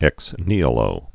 (ĕks nēə-lō, nī-, nĭ-)